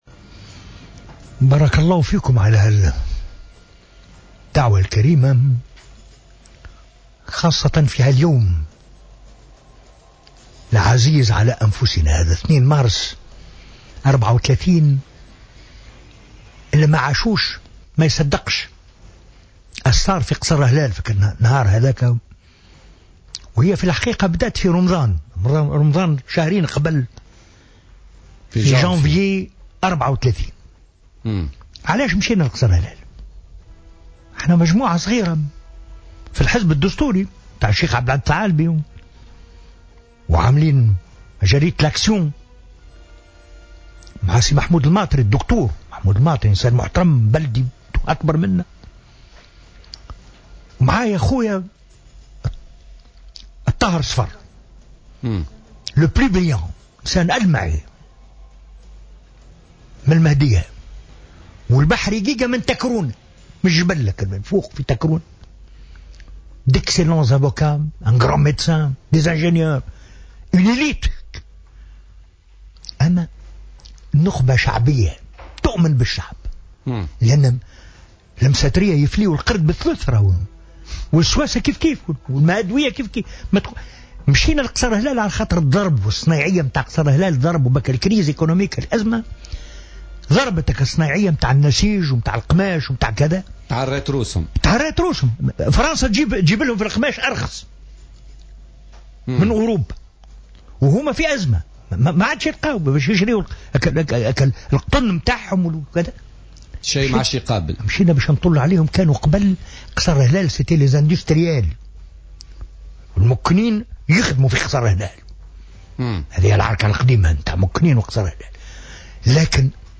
تحدّث الممثل المسرحي محمد رجاء فرحات، الذي تقمّص شخصية الزعيم الراحل الحبيب بورقيبة، خلال مداخلة له اليوم في برنامج "بوليتيكا" عن مؤتمر قصر هلال في 2 مارس 1934 والذي انبثق عنه الحزب الدستوري الجديد.